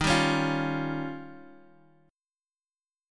Eb9 Chord
Listen to Eb9 strummed